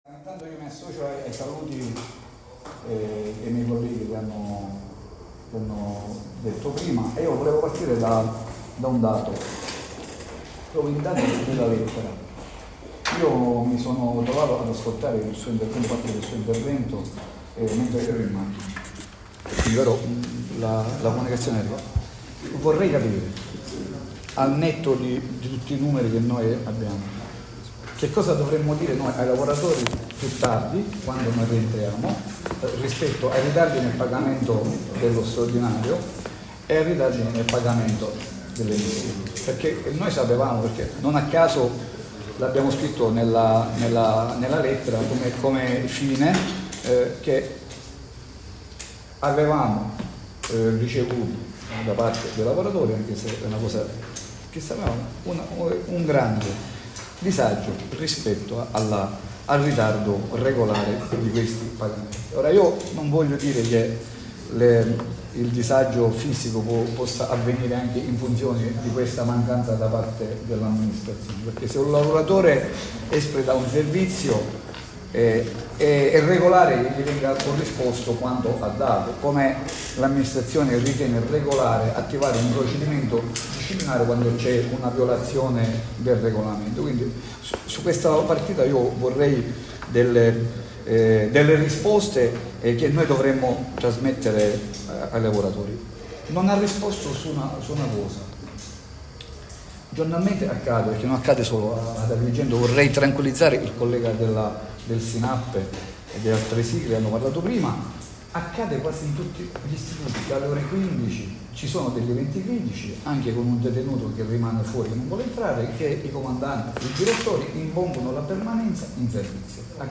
COMUNICATO ESITI DELL'INCONTRO AL PRAP
INTERVENTO UIL 19 SETT 24.mp3